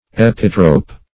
Search Result for " epitrope" : The Collaborative International Dictionary of English v.0.48: Epitrope \E*pit"ro*pe\, n. [L., fr. Gr.